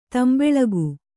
♪ tambeḷagu